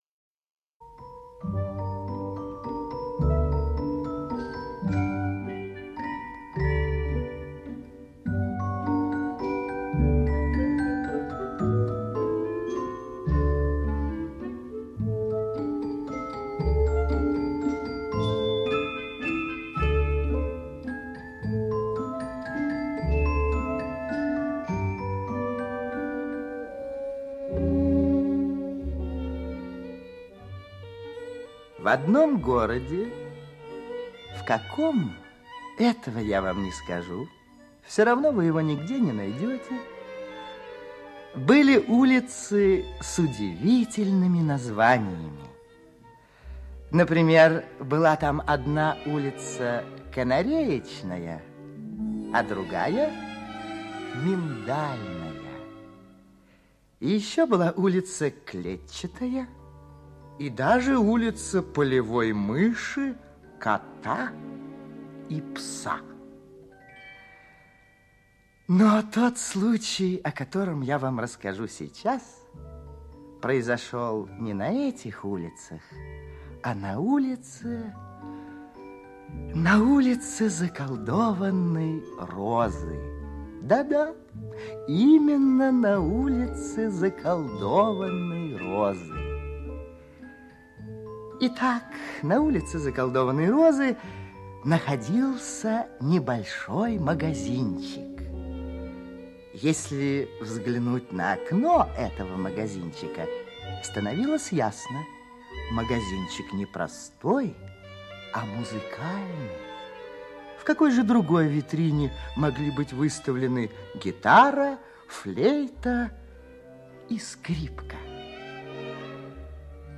Музыкальный магазинчик - аудиосказка Лягут - слушать онлайн